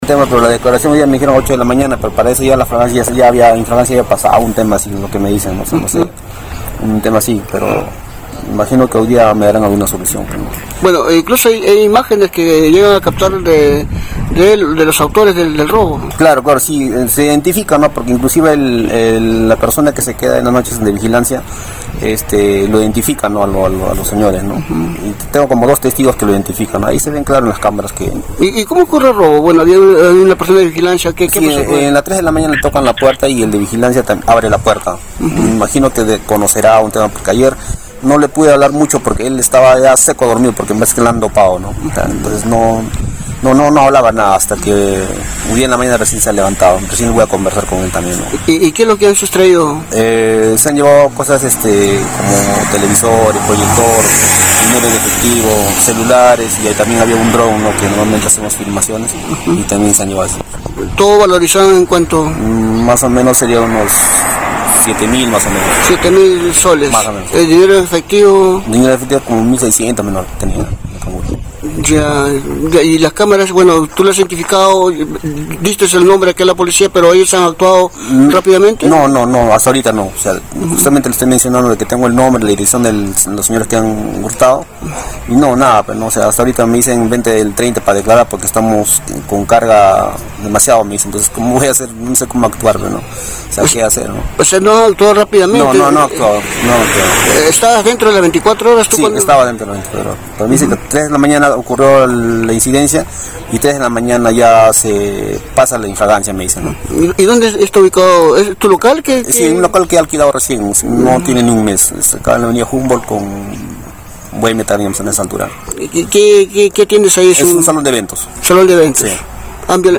7-RAY-DECLARACIONES.mp3